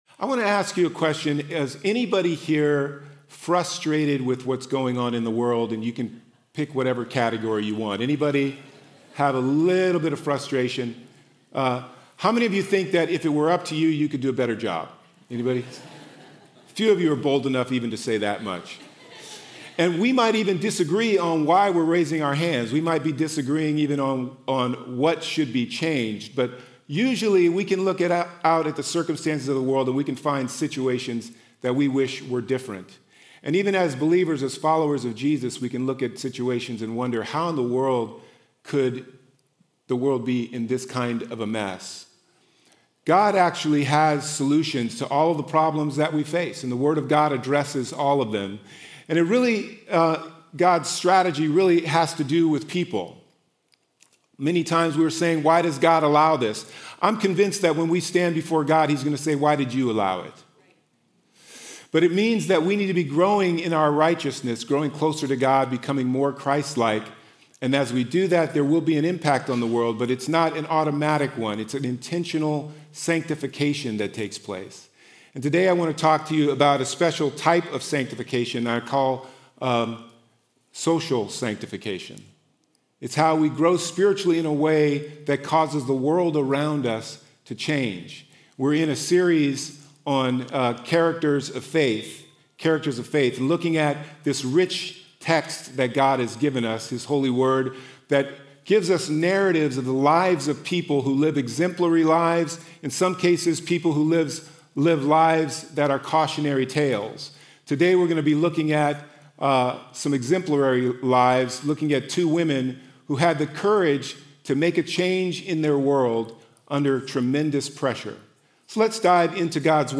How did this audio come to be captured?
Weekend Messages at Renaissance Church in Summit, NJ